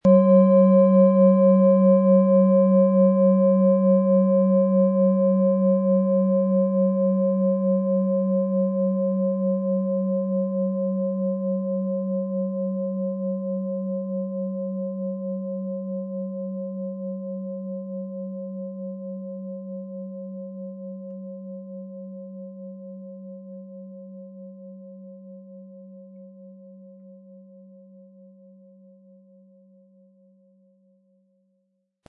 OM Ton
Nach uralter Tradition von Hand getriebene Klangschale.
• Tiefster Ton: Biorhythmus Geist
MaterialBronze